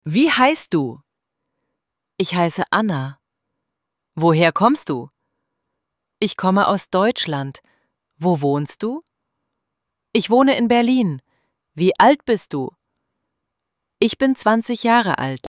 ElevenLabs_Text_to_Speech_audio-37.mp3